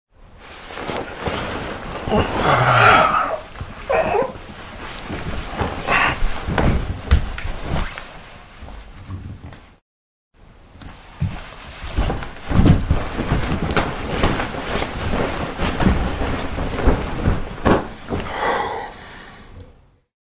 Звуки кровати
Звуки пробуждения и подъема мужчины